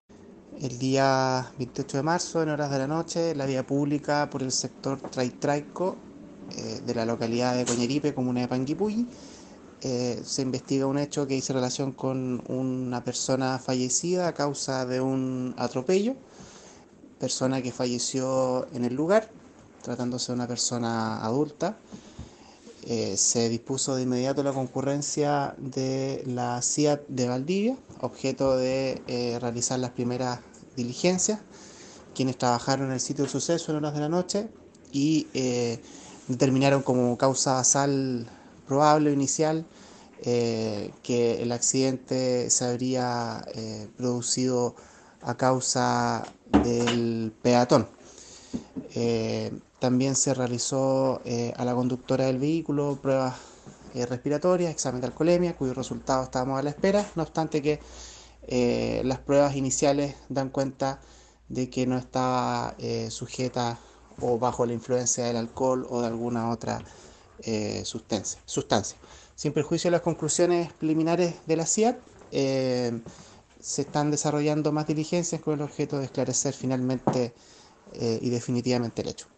fiscal subrogante de Panguipulli, Sebastián Prokurica, comentó que dirige una investigación por la muerte de un peatón por atropello en esa comuna. El fiscal dispuso la concurrencia de la SIAT de Carabineros, que luego del trabajo en el sitio del suceso estableció preliminarmente que la responsabilidad en el accidente fue del propio peatón.